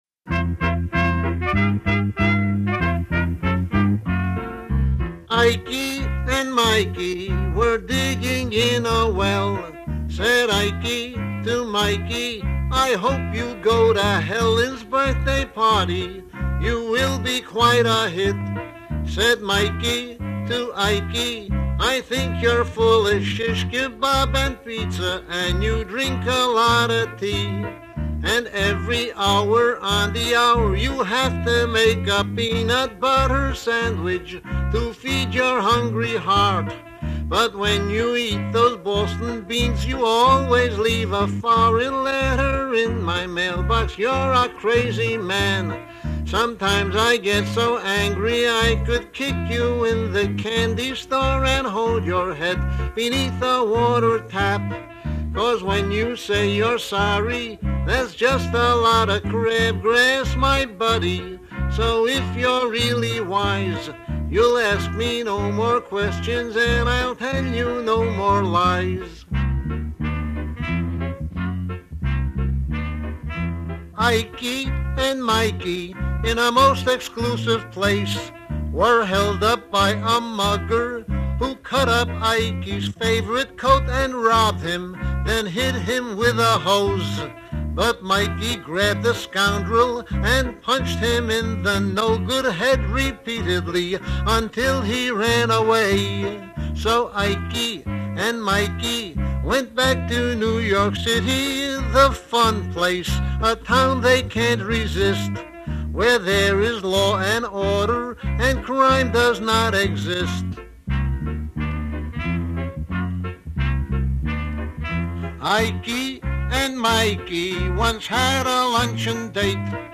double entendre party record